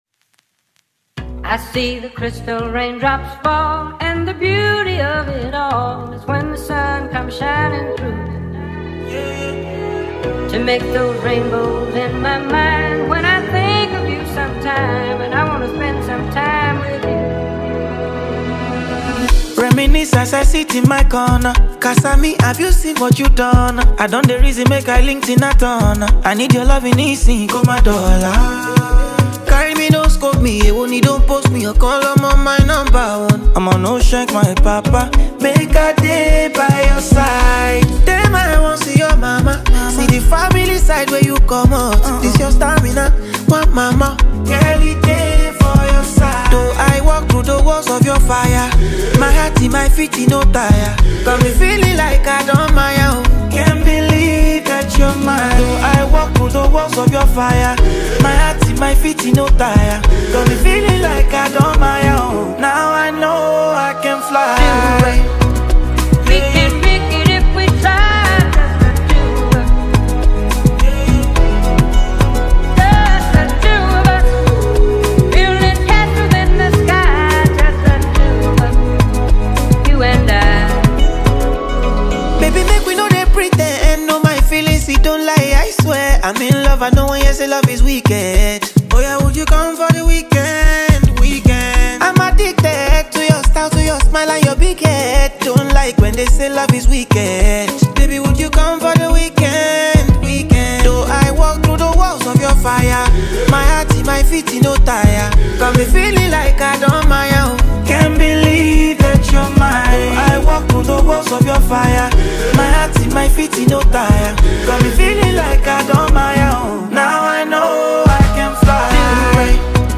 Afro-fusion